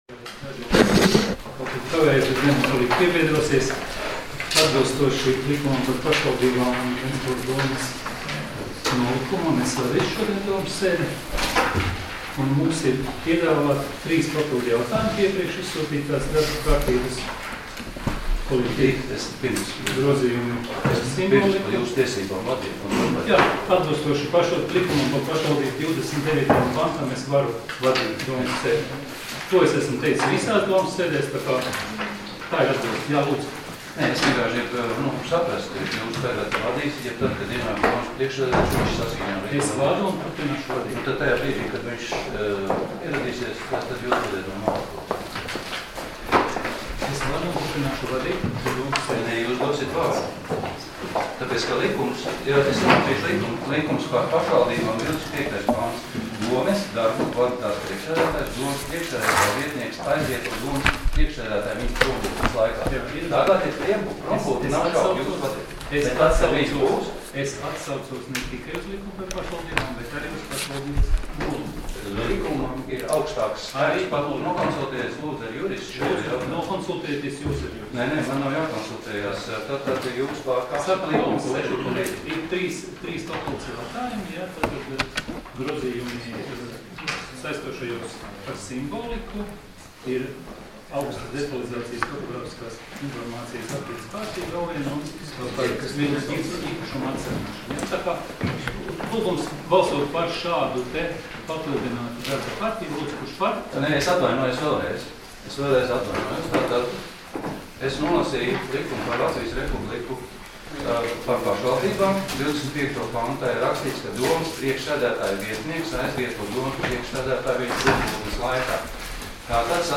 Domes sēdes 12.12.2019. audioieraksts